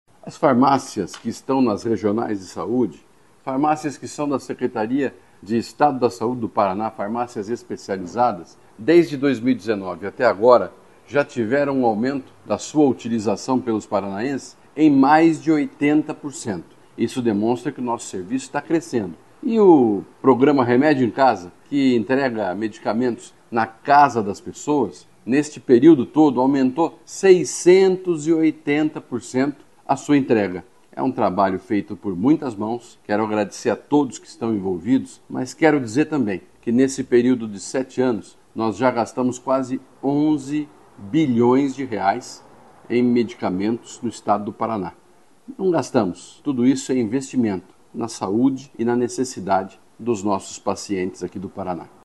Sonora do secretário da Saúde, Beto Preto, sobre a Assistência Farmacêutica do Paraná